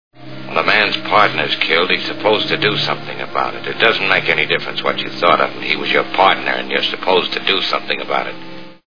The Maltese Falcon Movie Sound Bites